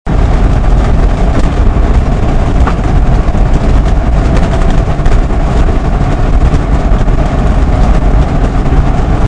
Звук при троении двигателя